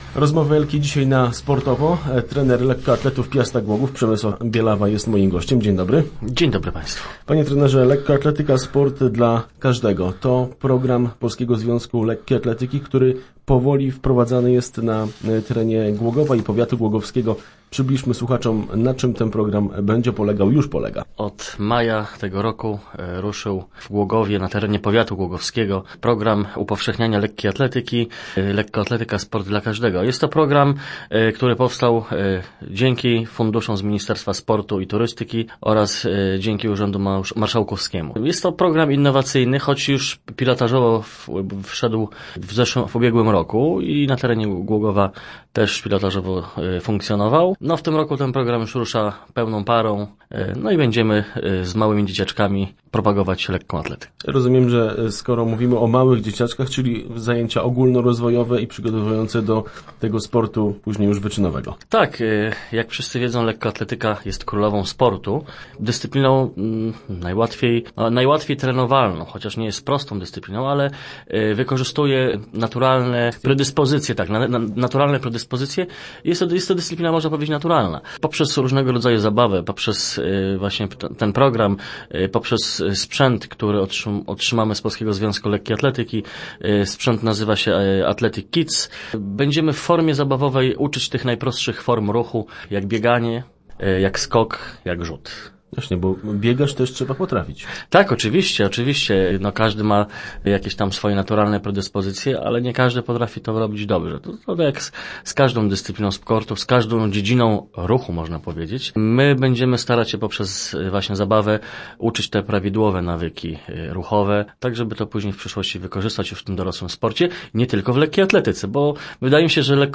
Sport